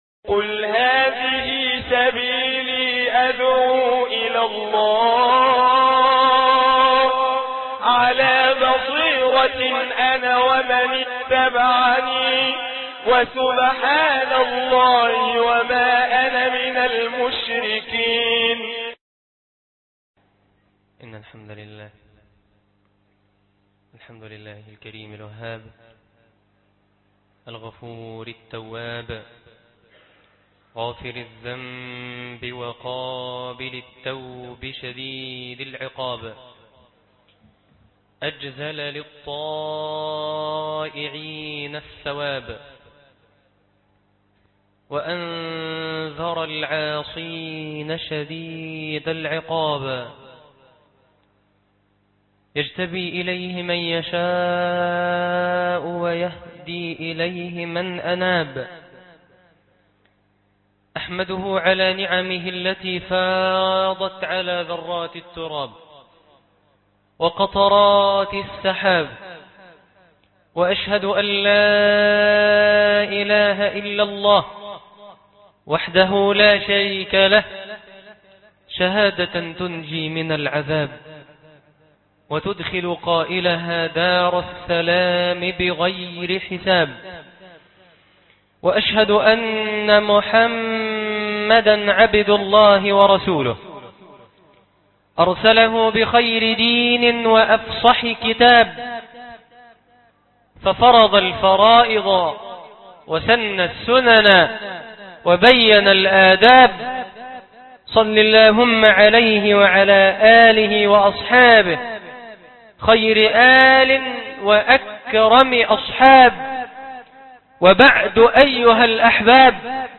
مشهد رأيته- خطب الجمعة